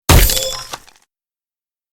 Magic Poof 2
Magic Poof 2 is a free sfx sound effect available for download in MP3 format.
yt_GblAcDg2-D0_magic_poof__2.mp3